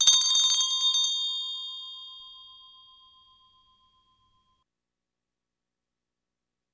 Old Style Door Bell
Bell Bells Ding Hand-Bell Old-Fashion-Doorbell Servant-Bell Small sound effect free sound royalty free Sound Effects